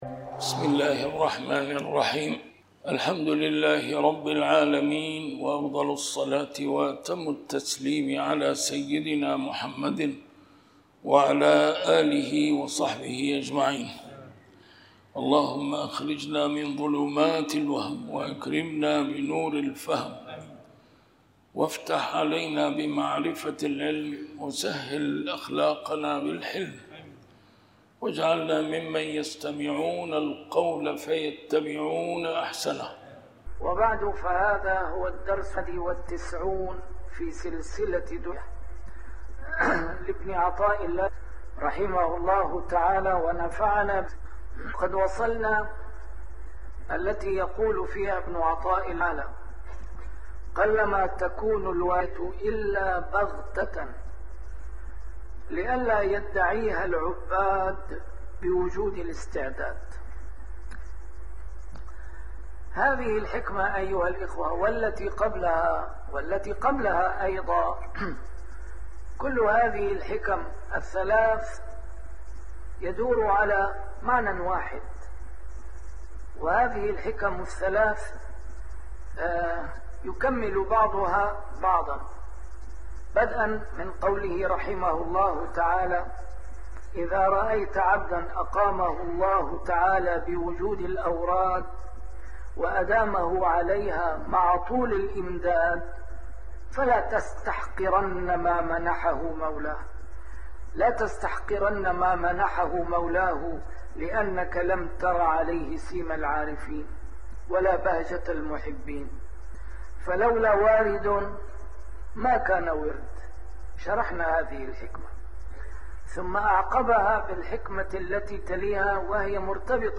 شرح الحكم العطائية - A MARTYR SCHOLAR: IMAM MUHAMMAD SAEED RAMADAN AL-BOUTI - الدروس العلمية - علم السلوك والتزكية - الدرس رقم 91 شرح الحكمة 69